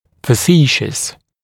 [fə’siːʃəs][фэ’си:шэс]шутливый, комический, забавный, весёлый, курьёзный